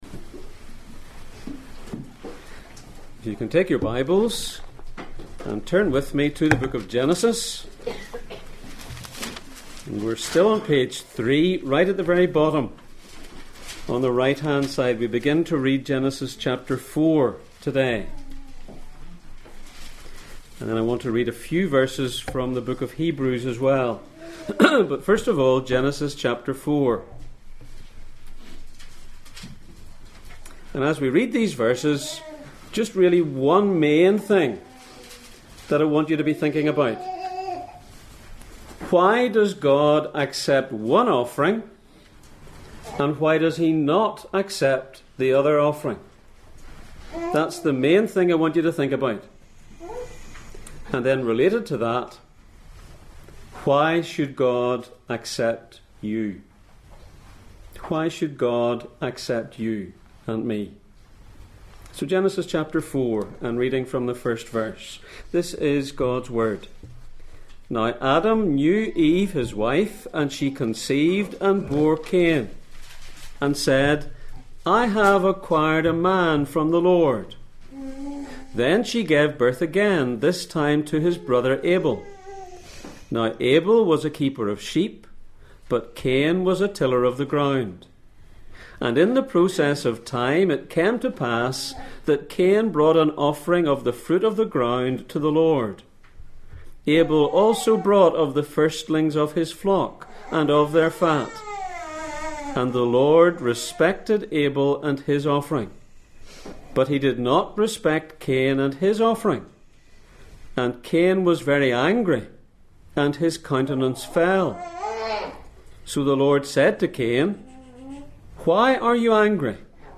Back to the beginning Passage: Genesis 4:1-8, Hebrews 11:1-4 Service Type: Sunday Morning